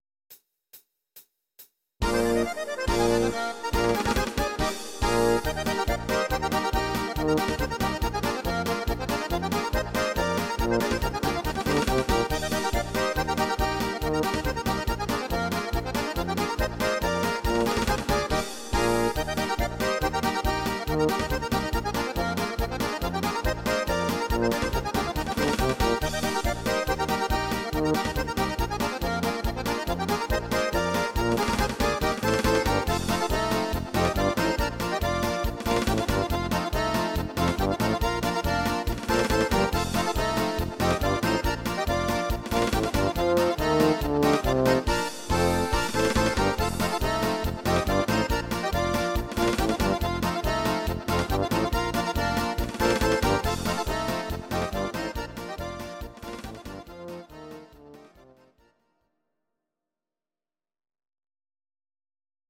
Audio Recordings based on Midi-files
Instrumental, Traditional/Folk, Volkstï¿½mlich